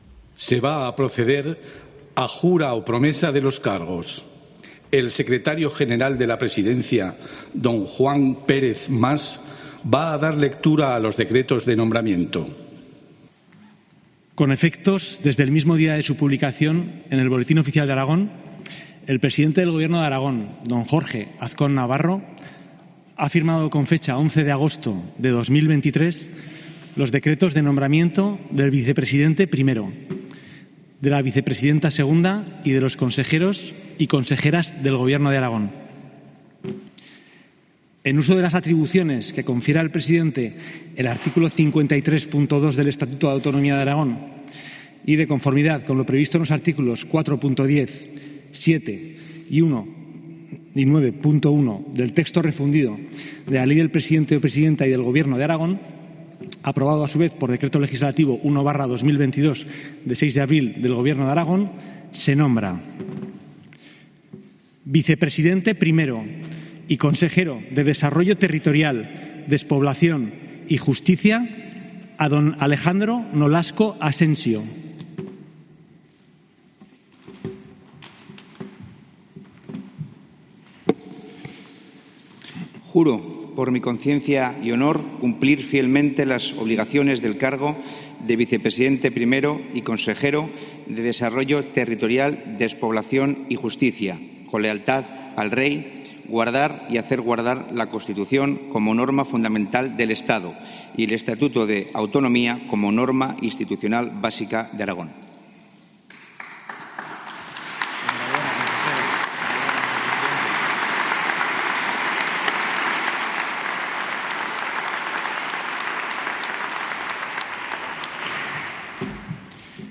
Así fue el acto de toma de posesión del Gobierno de Aragón en la Sala de la Corona del Pignatelli.